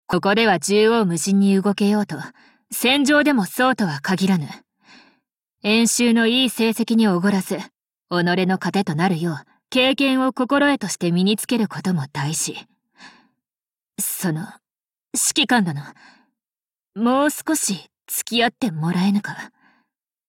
贡献 ） 协议：Copyright，其他分类： 分类:碧蓝航线:高雄语音 您不可以覆盖此文件。